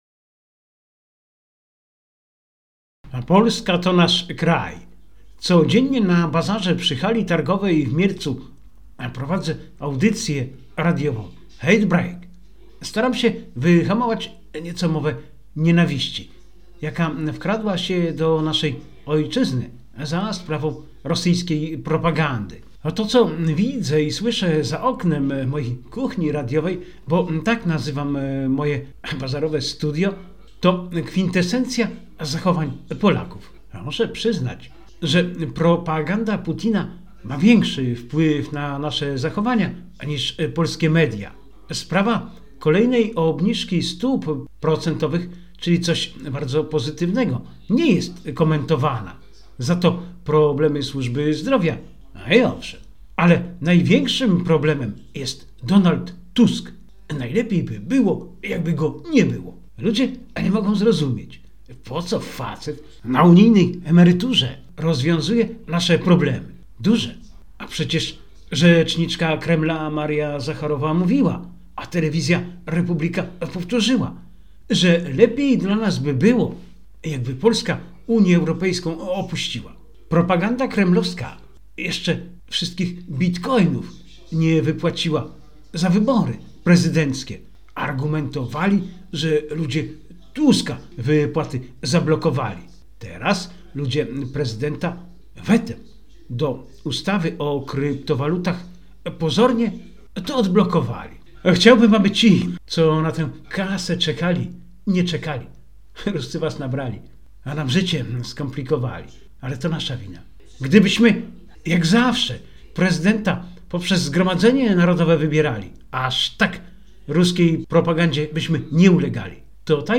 To, co widzę i słyszę za oknem mojej kuchni radiowej – bo tak nazywam moje bazarowe studio, to kwintesencja zachowań Polaków.